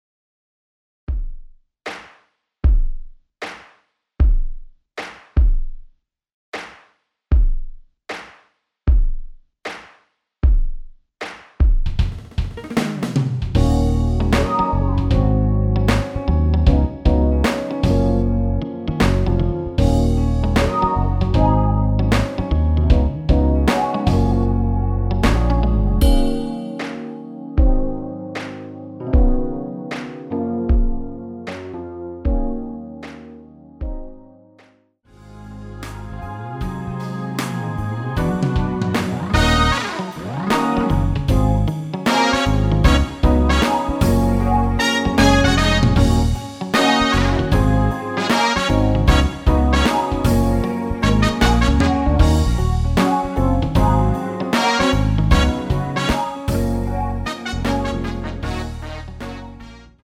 원키에서(-1)내린 MR입니다.
F#
앞부분30초, 뒷부분30초씩 편집해서 올려 드리고 있습니다.